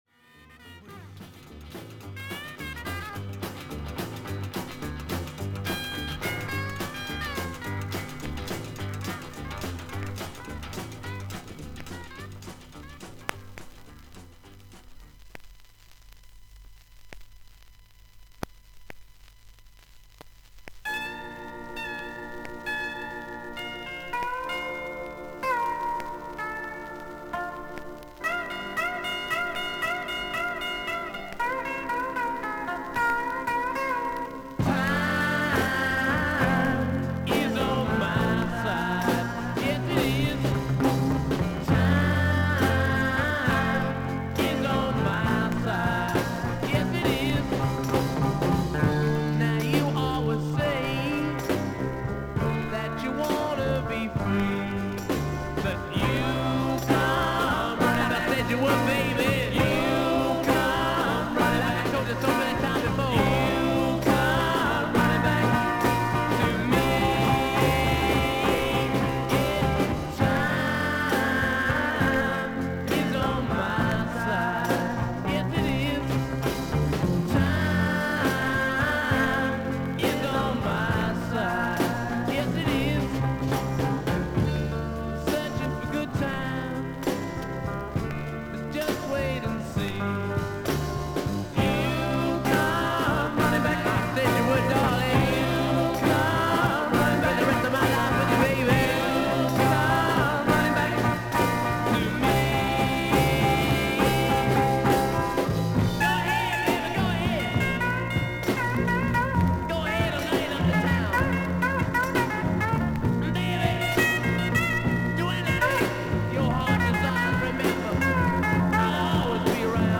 A3終わりからA5中盤に軽い周回ノイズあり。A3とA4の曲間に2回パチノイズあり。
少々サーフィス・ノイズあり。クリアな音です。